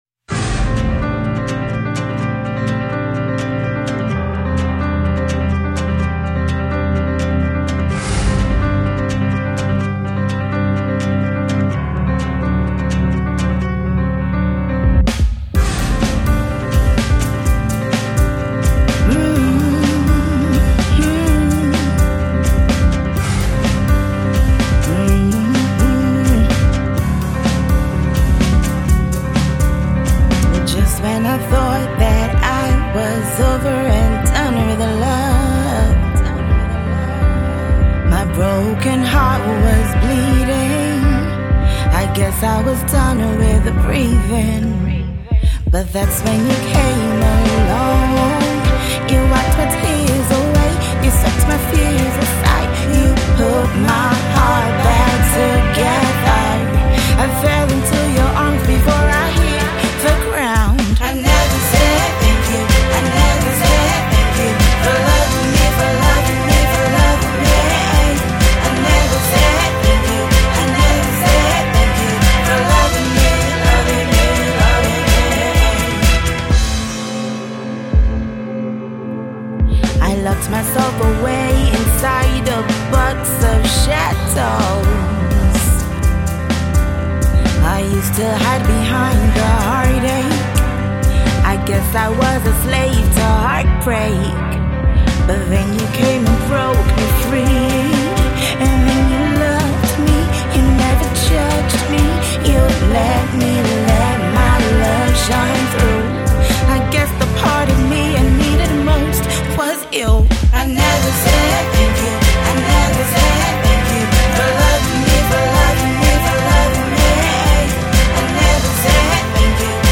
Jazz and Soul songstress